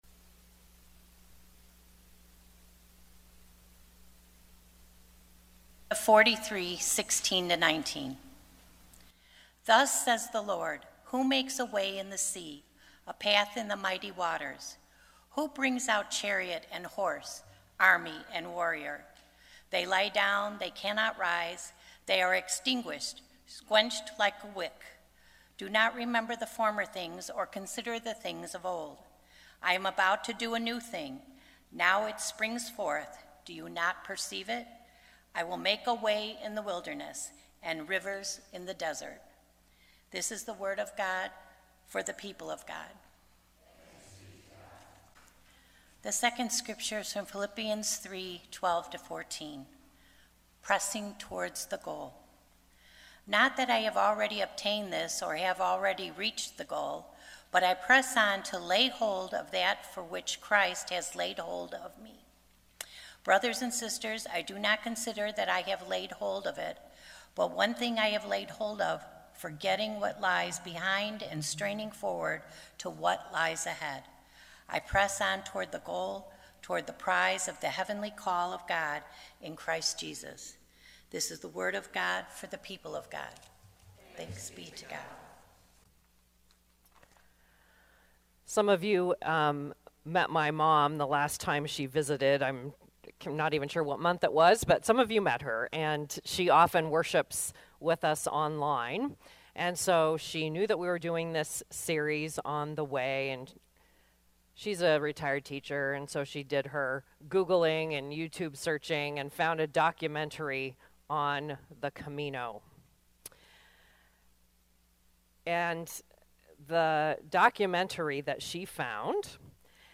Sermons | Faith United Methodist Church of Orland Park